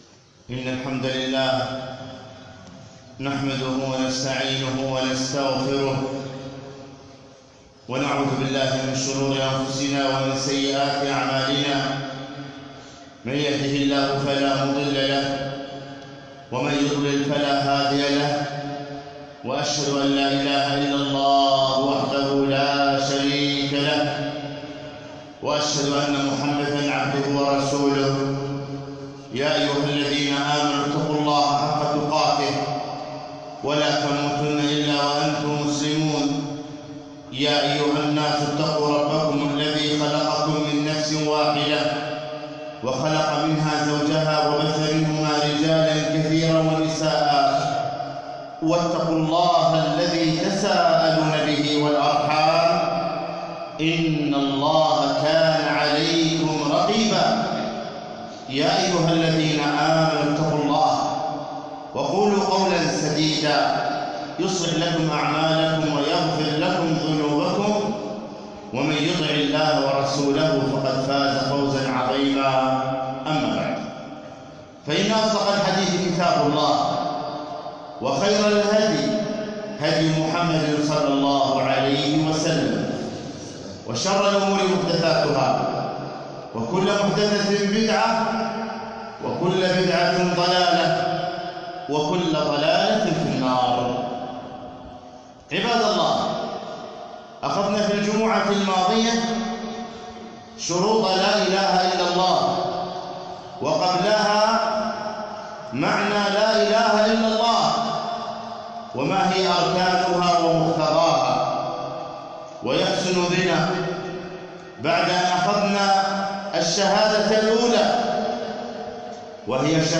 خطبة - شهادة أن محمدا رسول الله